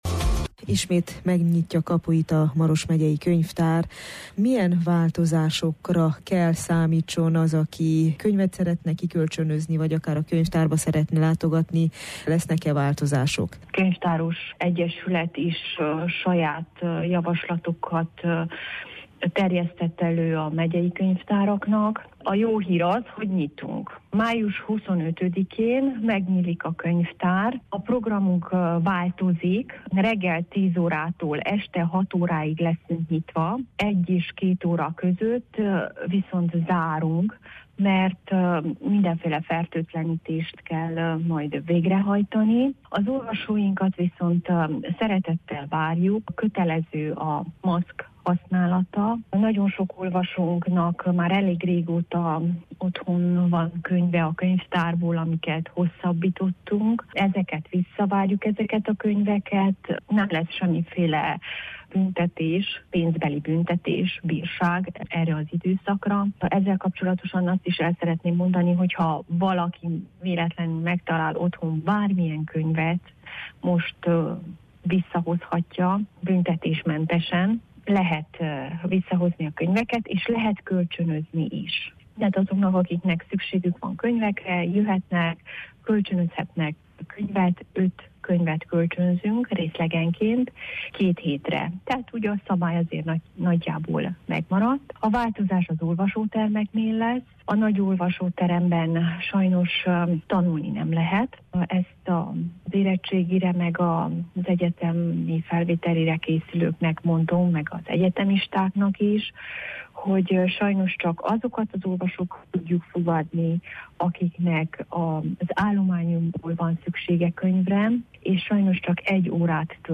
könyvtárost kérdezte